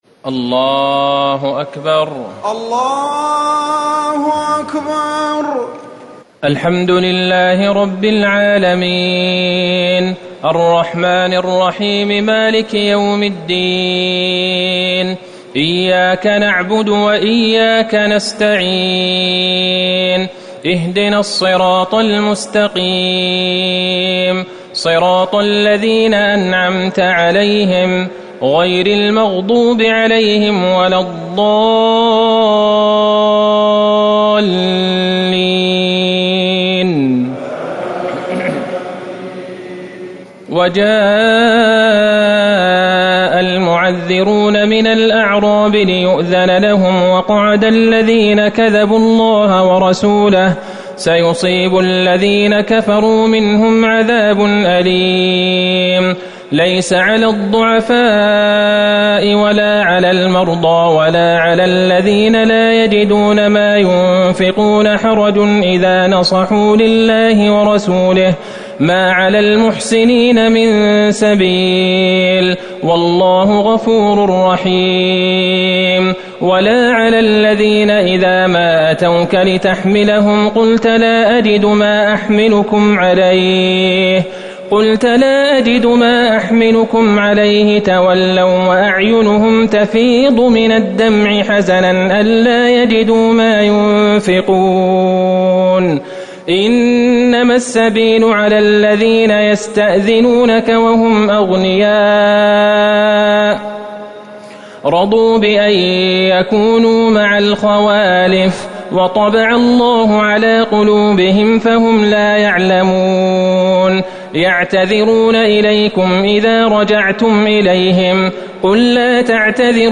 ليلة ١٠ رمضان ١٤٤٠ من سورة التوبة ٩٠ - ٢٦ يونس > تراويح الحرم النبوي عام 1440 🕌 > التراويح - تلاوات الحرمين